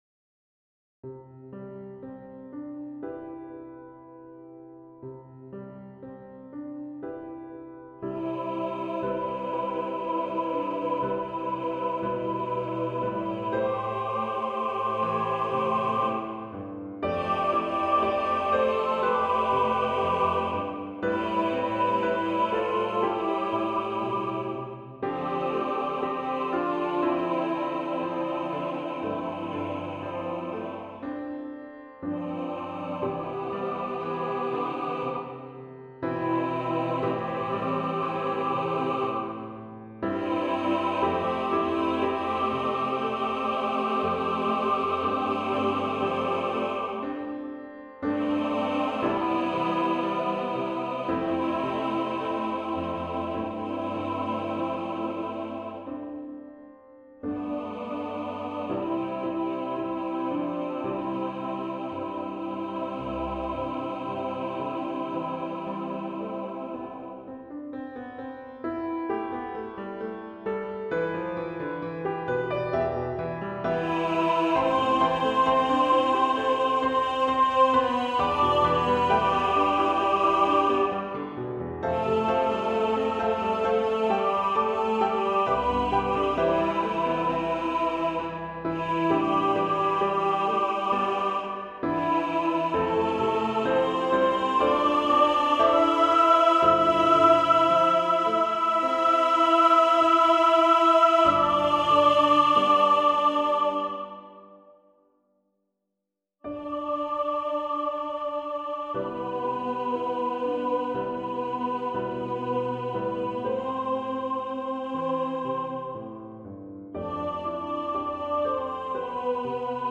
SATB with piano